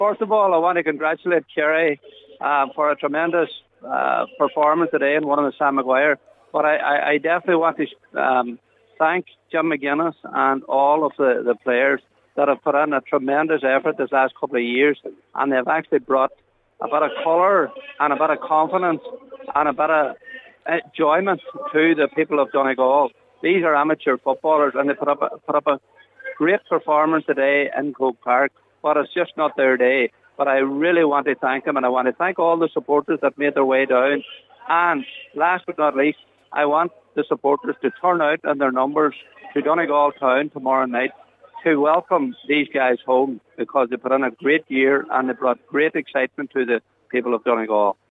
Speaking from Croke Park a short time ago, Cllr Paul Canning said the team has given the county a huge lift over the past number of weeks, and they can be proud of what they have achieved.